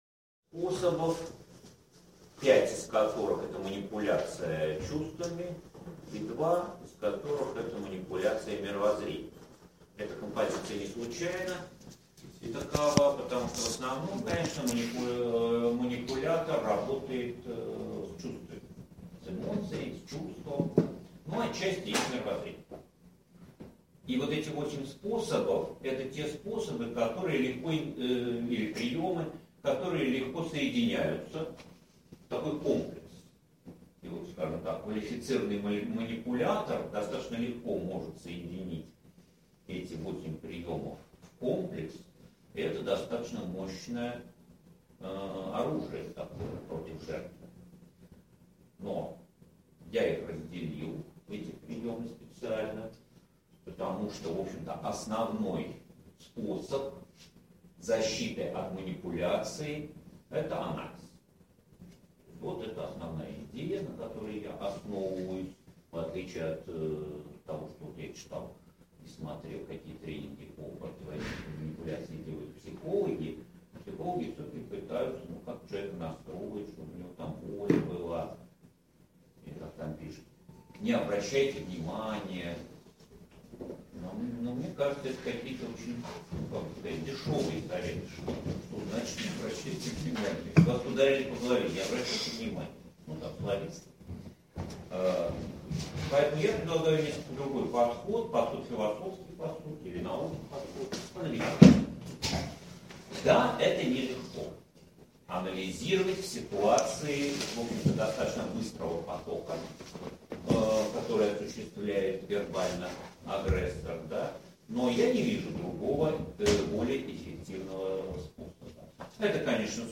Аудиокнига 8 эффективных способов манипуляции людьми и защита от них | Библиотека аудиокниг